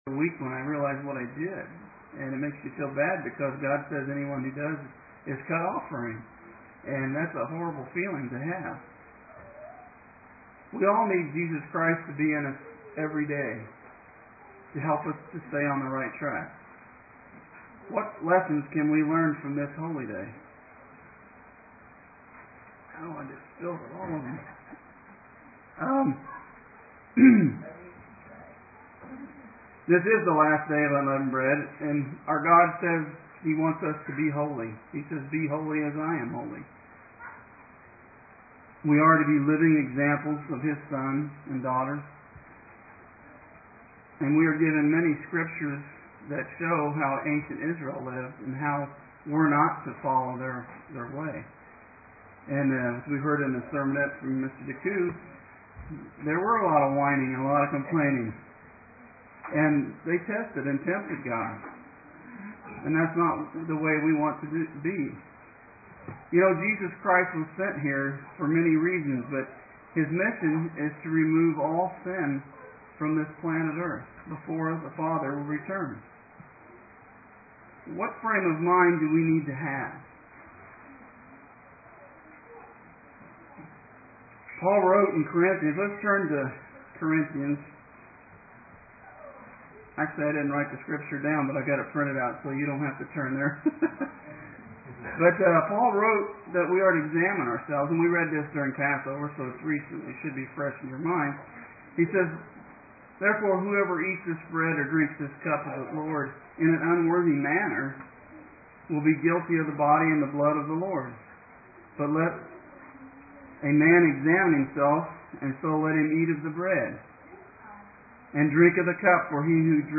Do we see ourselves as God sees us? This message was given on the Last Day of Unleavened Bread